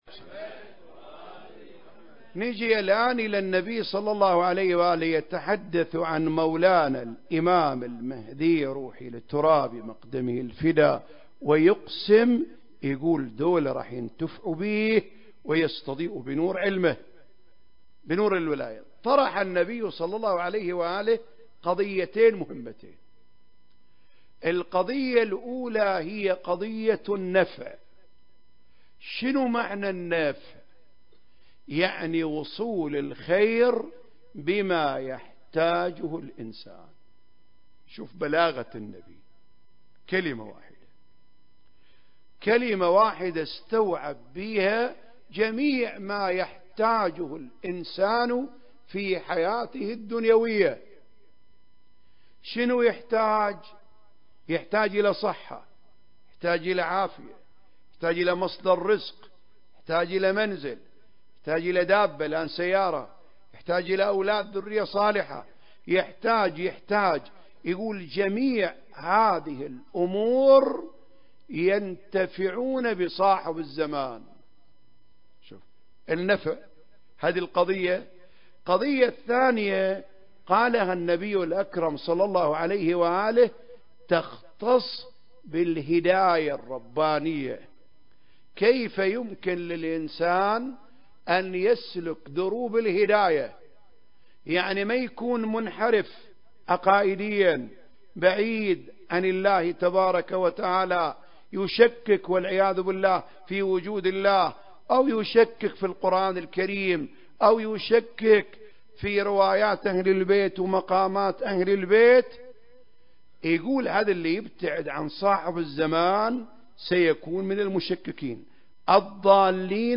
سلسلة محاضرات في رحاب الإمام صاحب الزمان (عجّل الله فرجه) (1) المكان: الحسينية الهاشمية/ الكويت التاريخ: 2023